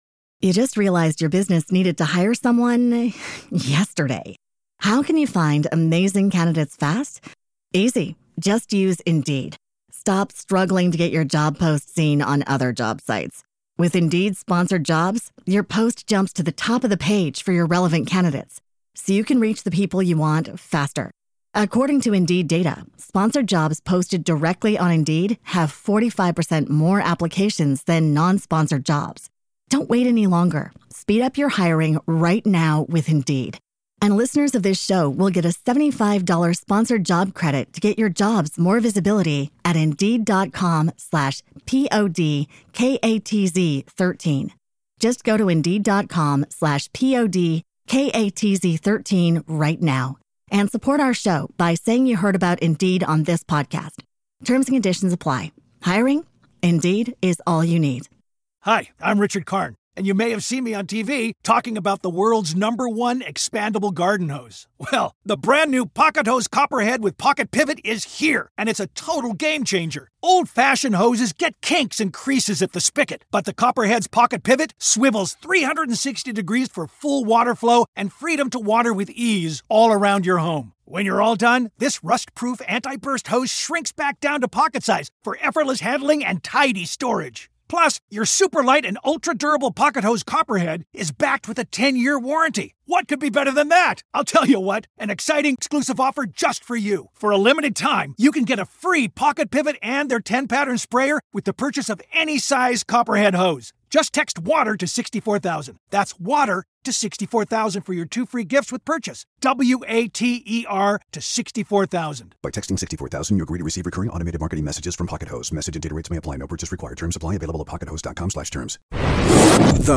(Interview starts at 9 minutes 56 seconds)